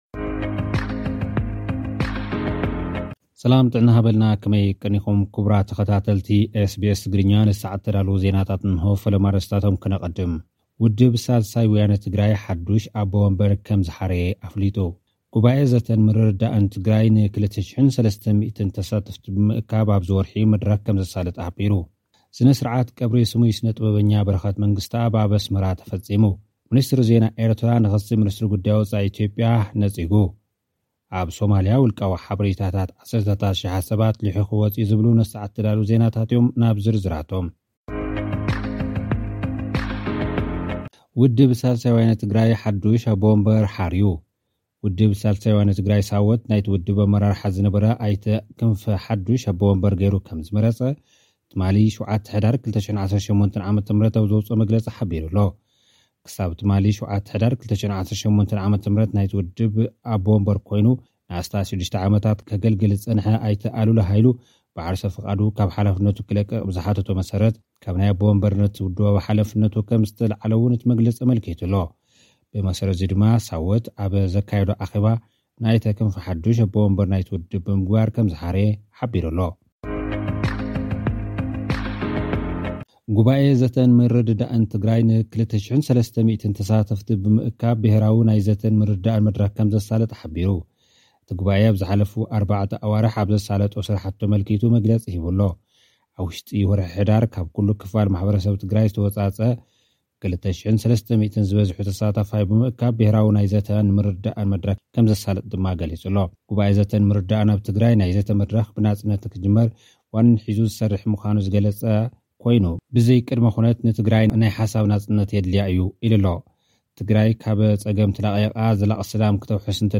ስነ ስርዓት ቐብሪ ስሙይ ስነጥበበኛ በረኸት መንግስትኣብ ተፈጺሙ። (ጸብጻብ)